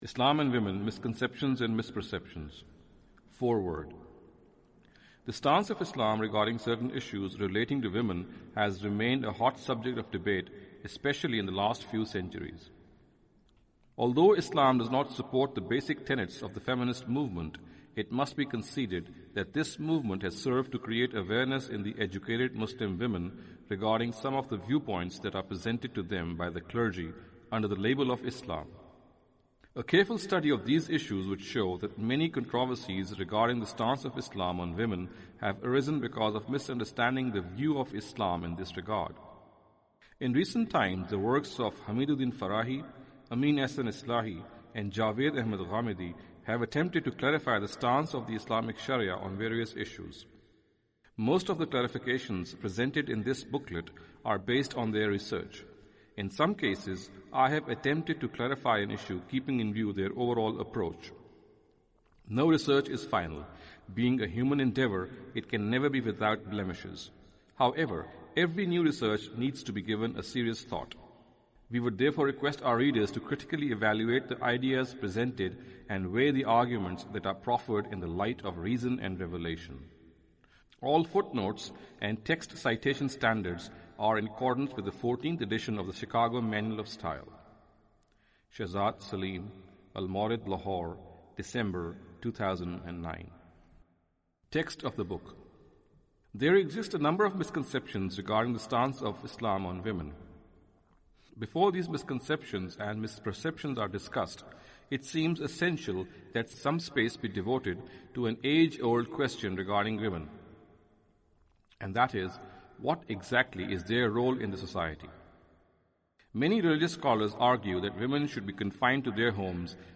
Audio book of English translation of Javed Ahmad Ghamidi's book "Islam and Women".